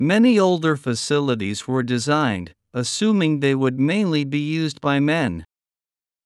２）スロー（前半／後半の小休止あり）